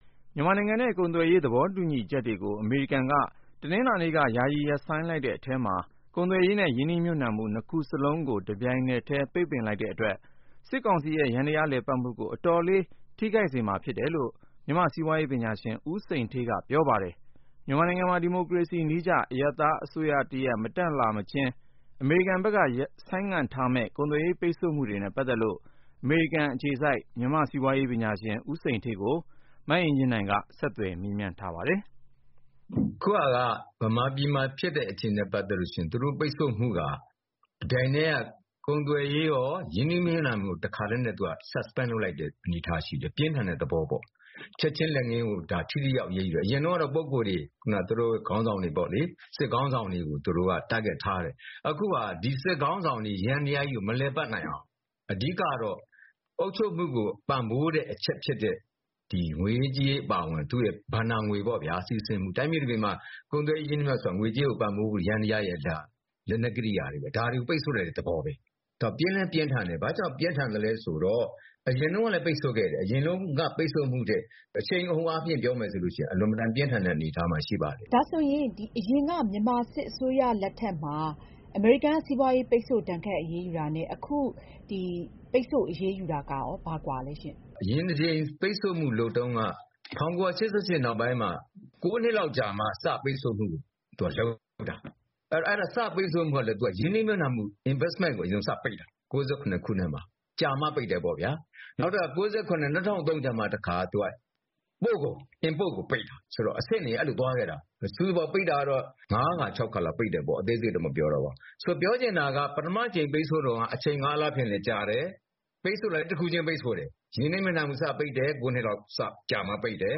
အမေရိကန် စီးပွားရေးဒဏ်ခတ်မှု စစ်ကောင်စီအပေါ် ဘယ်လောက်ထိရောက်မလဲ (ဆက်သွယ်မေးမြန်းချက်)